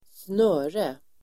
Uttal: [²sn'ö:re]